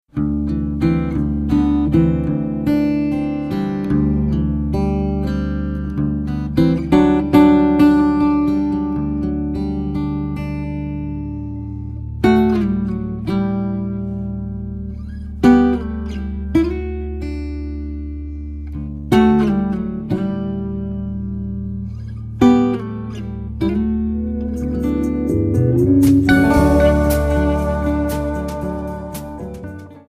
Alternative,Blues